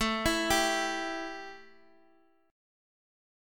A 5th 7th